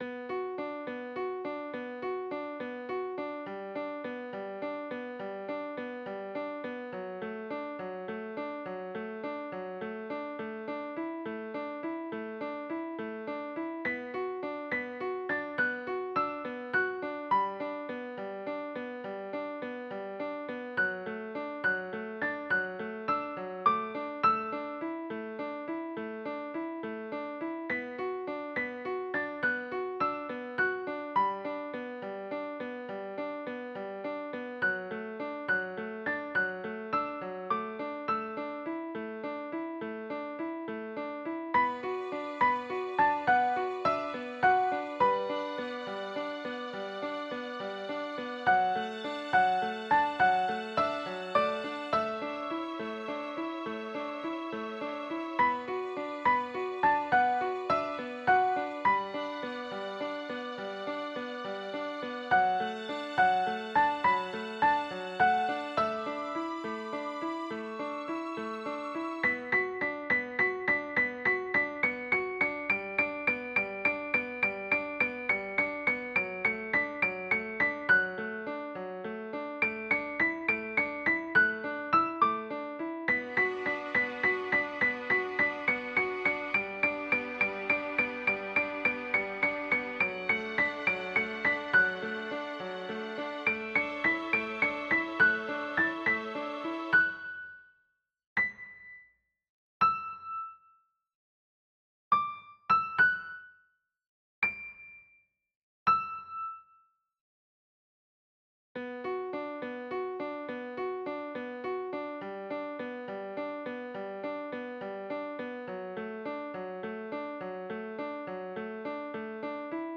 ロ短調（Bマイナー）8分の12拍子BPM104
高音のピアノメロディ良いね。それから相変わらずストリングスでの盛り上げ方がうまい！